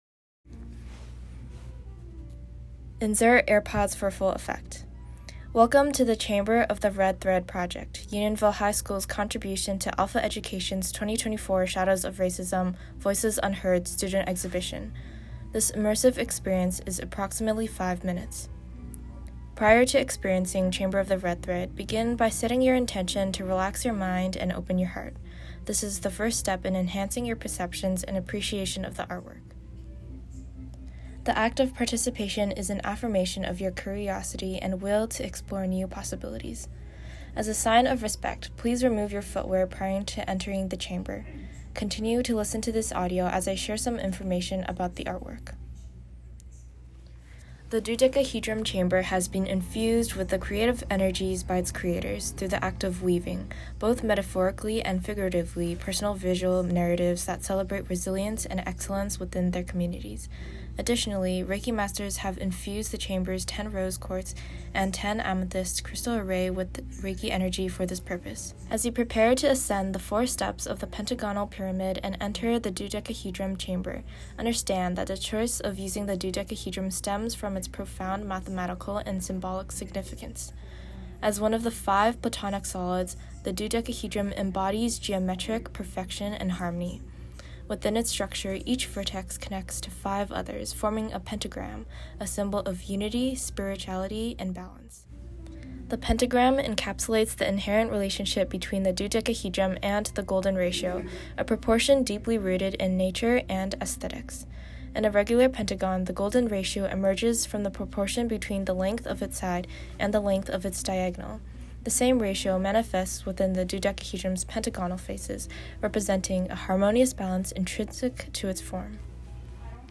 (Meditation begins at time index 2:25)
Performed by the UHS orchestra.
To enrich the narrative, students created a meditative soundscape and voice recordings controlled by touch-sensitive sensors, enhancing the visual experience with auditory elements.